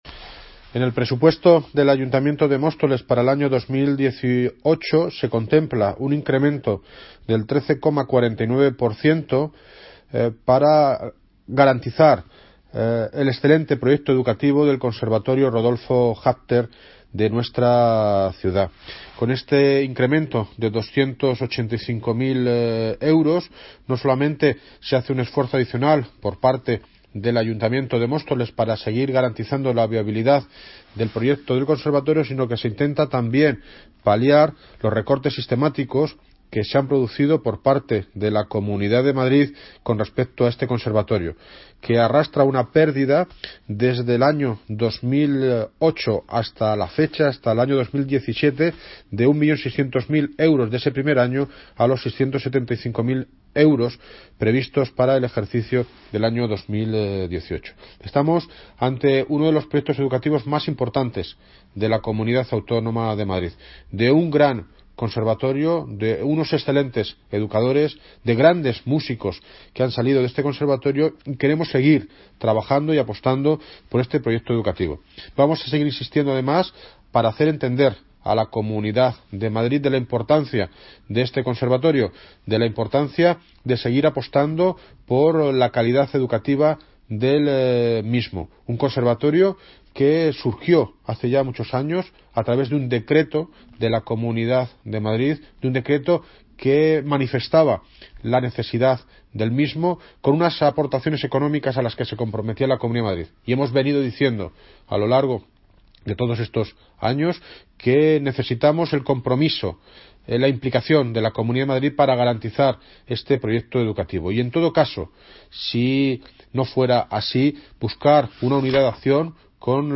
Audio - David Lucas (Alcalde de Móstoles) Sobre Conservatorio